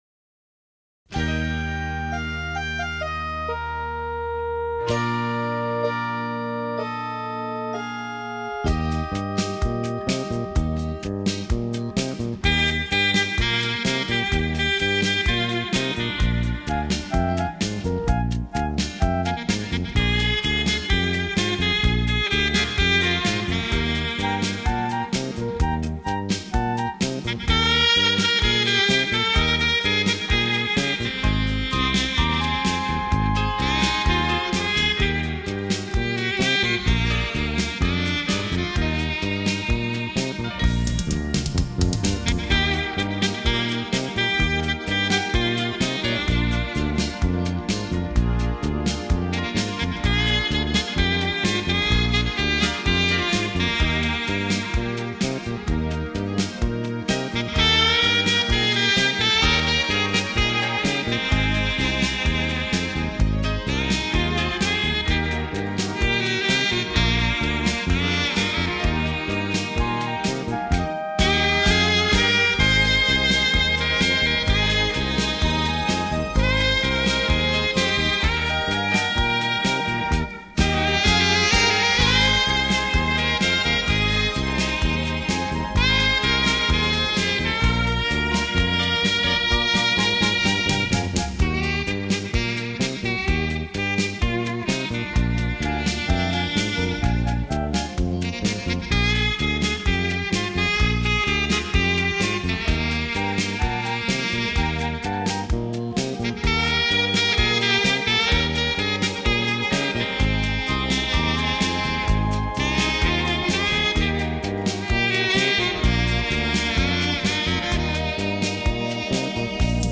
时而欢欲时而低沉的音符
送出深情浪漫
去领略那乐韵的醇美和节奏的飘舞飞扬…